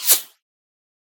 whine_4.ogg